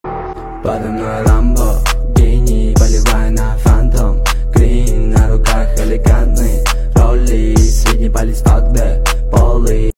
• Качество: 321 kbps, Stereo